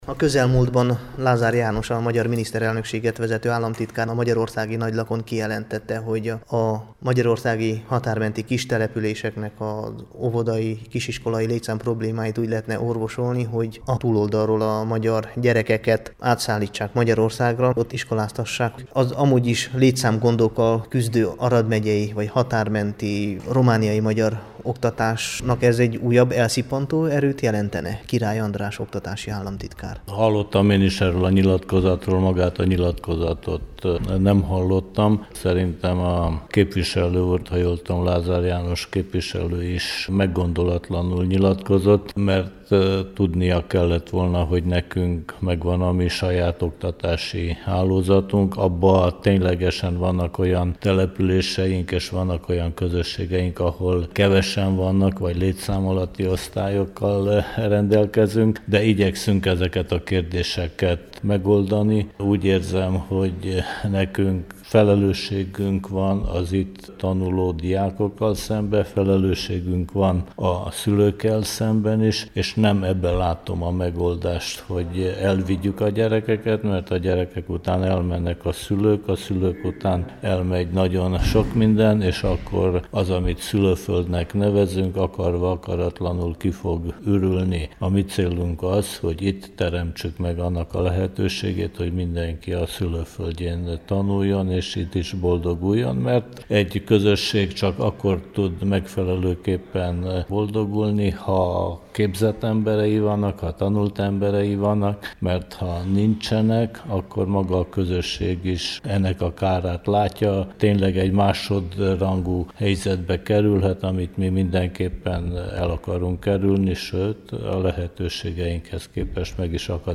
Király András RMDSZ-es tanügyi államtitkár nem tartja jó ötletnek a gyerekek magyarországi iskoláztatását, mert azzal „kiürül a szülőföld”. Szerinte a hazai magyar oktatási hálózat megerősítésén kell dolgozni, s megteremteni a lehetőségét, hogy minden gyerek magyarul tanulhasson idehaza.